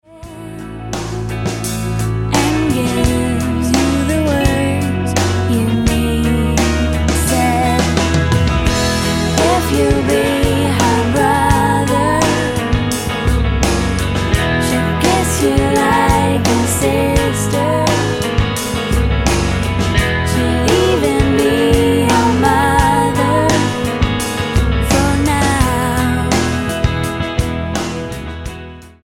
STYLE: Pop
floating vulnerable vocals
drums